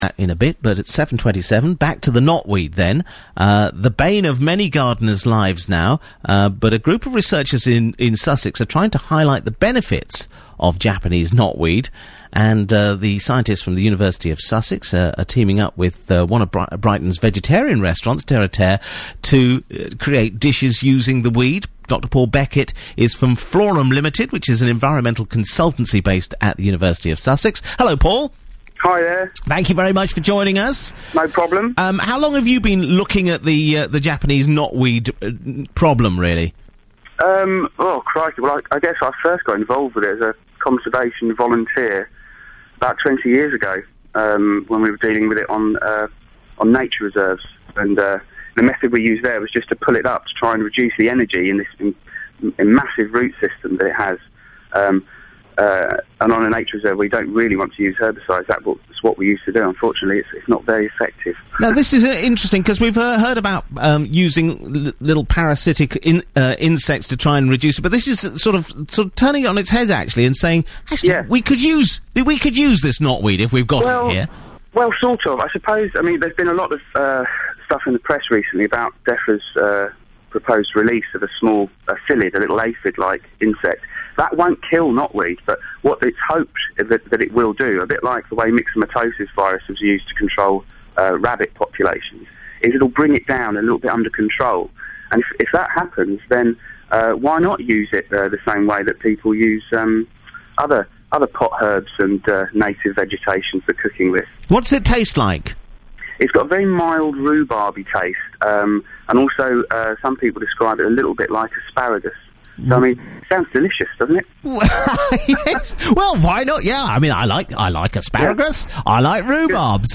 On a recent BBC Sussex radio appearance for the campaign